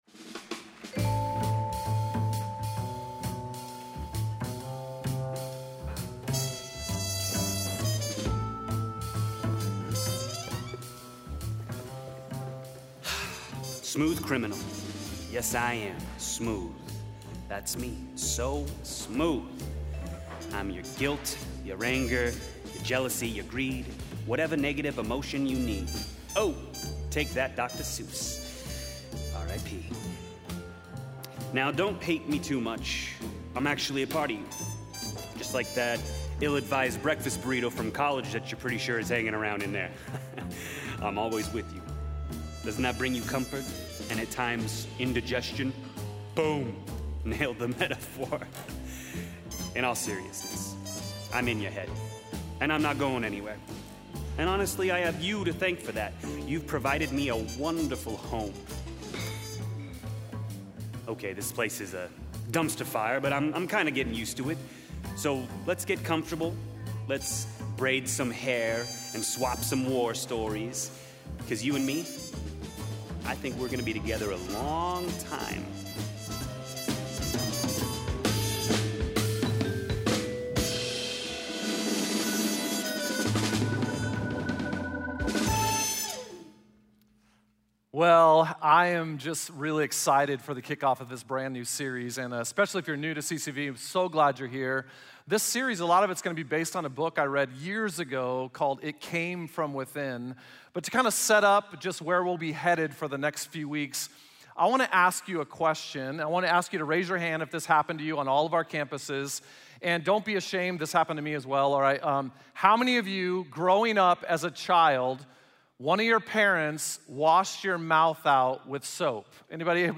Share this Message: Share via: Other Messages in this Series Straight to the Heart Guilt Jealousy Anger Greed Message Only Full Service Related Downloads Download Audio Download Sermon Note Download Discussion Guide